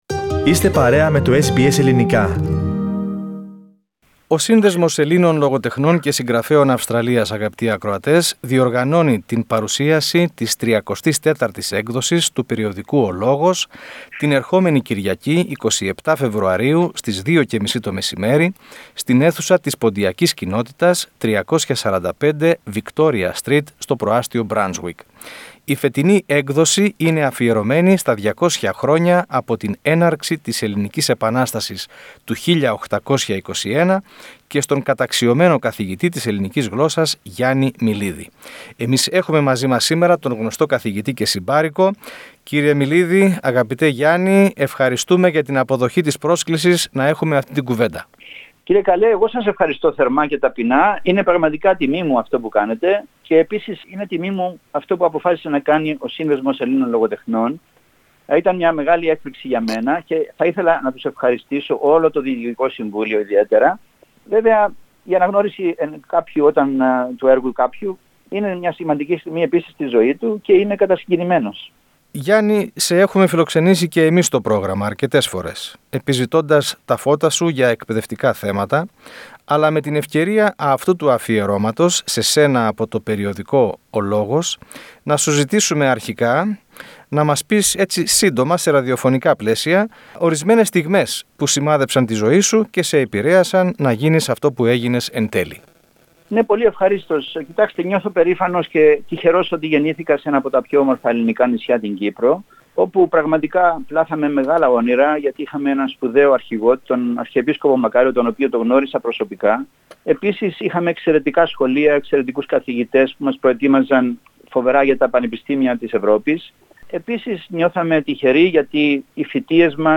Ακούστε ολόκληρη τη συνέντευξη στο podcast.